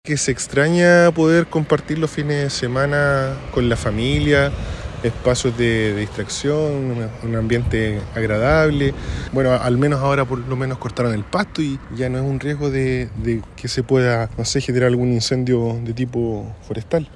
Vecinos del Pabellón de La Araucanía lamentaron que a un año del cierre del pabellón, no se haya podido poner nuevamente en funcionamiento.